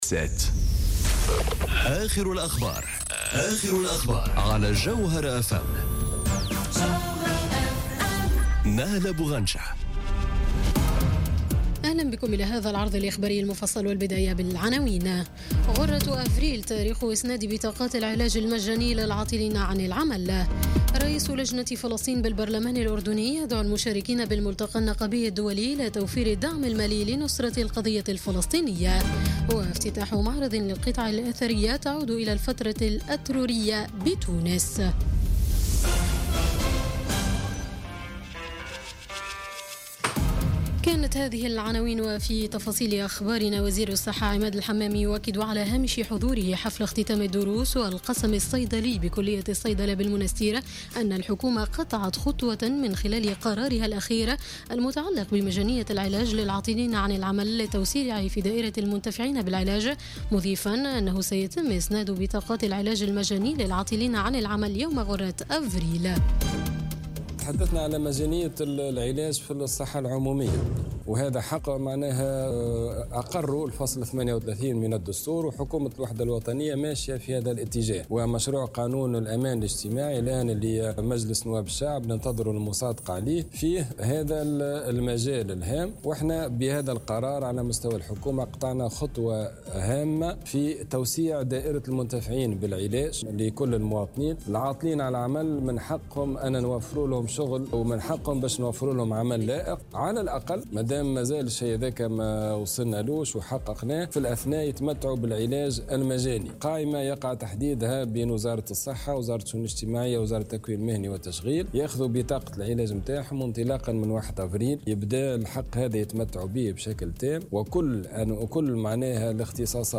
نشرة أخبار السابعة مساءً ليوم السبت 20 جانفي 2018